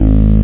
1 channel
303-growl3.mp3